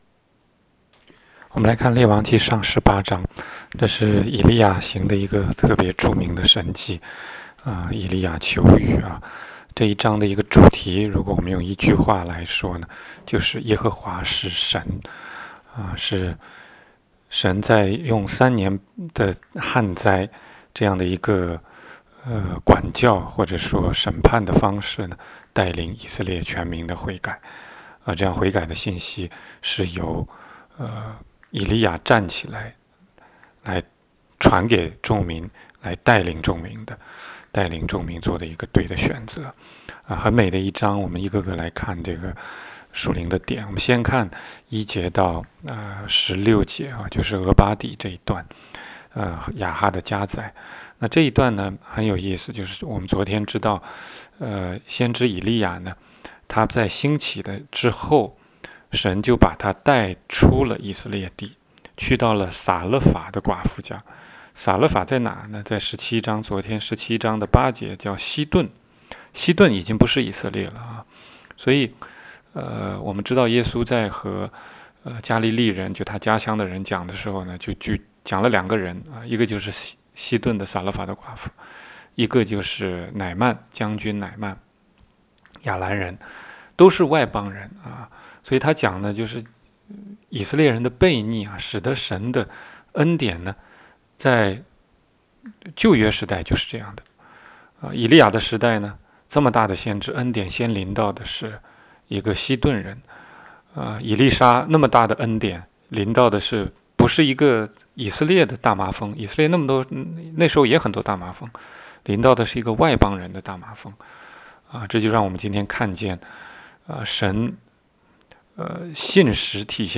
16街讲道录音 - 每日读经-《列王纪上》18章